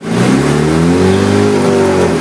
Index of /server/sound/vehicles/lwcars/uaz_452